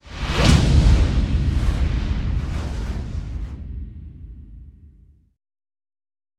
Здесь вы найдете плавные затухания, исчезающие эффекты и другие варианты финальных аудиоштрихов.
Звук завершения видео